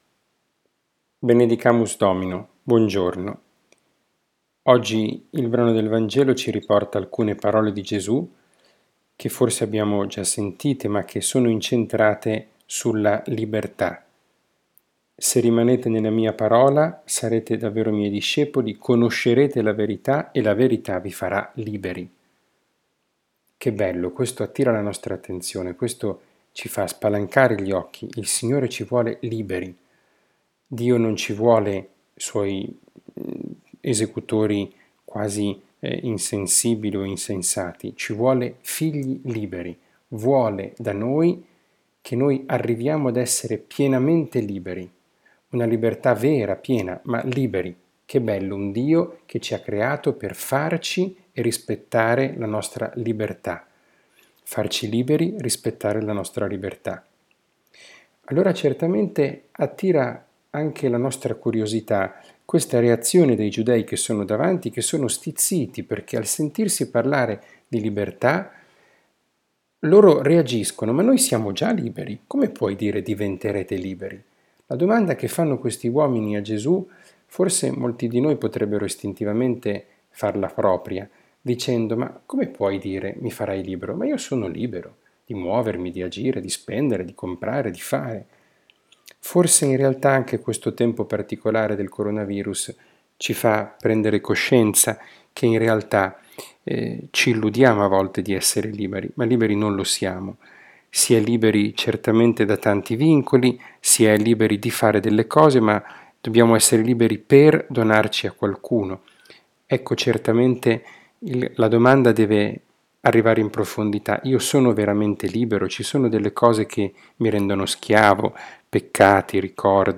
catechesi, Parola di Dio, podcast